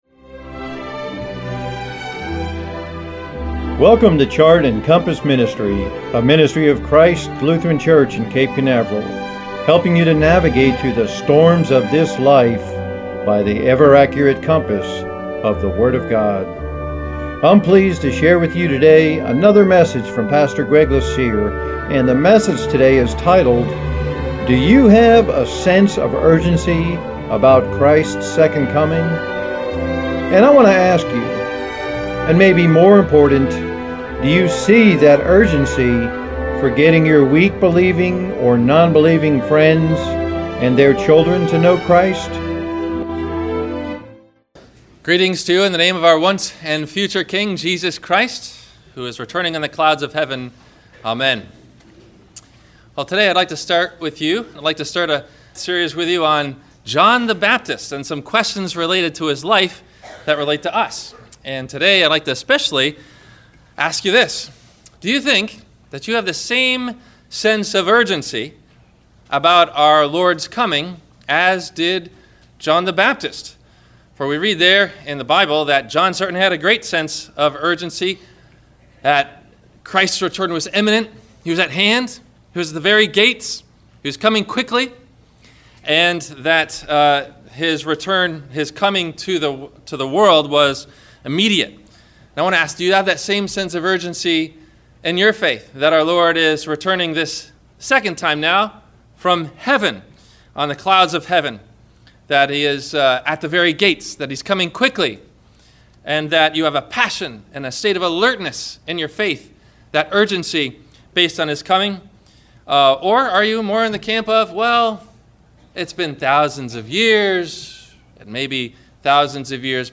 Do You Have a Sense of Urgency about Christ’s Second Coming? – WMIE Radio Sermon – July 02 2018 - Christ Lutheran Cape Canaveral